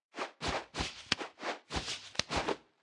Media:Sfx_Anim_Ultimate_Medic.wav 动作音效 anim 在广场点击初级、经典、高手、顶尖和终极形态或者查看其技能时触发动作的音效
Sfx_Anim_Ultimate_Medic.wav